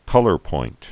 (kŭlər-point)